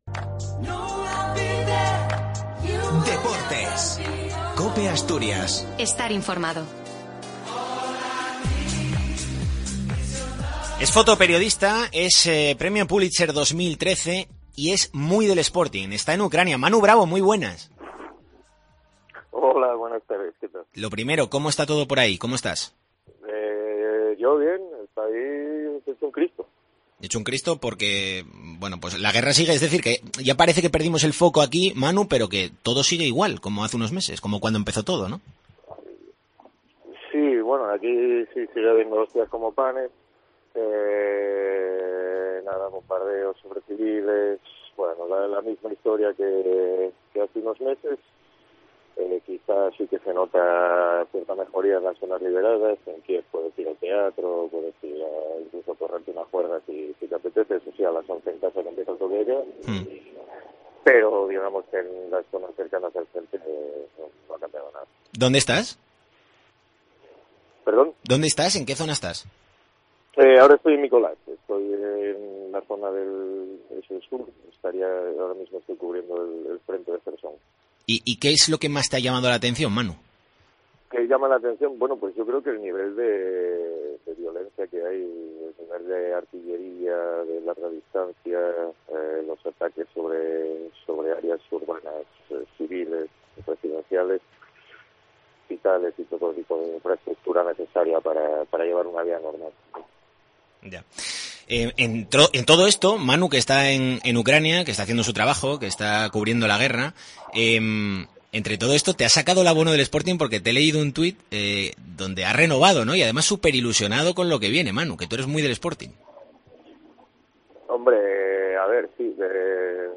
ENTREVISTA DCA
Fotoperiodista y premio Pullitzer 2013, Manu Brabo nos cuenta desde Ucrania su ilusión por la llegada de Orlegi tras sacar su abono para la nueva temporada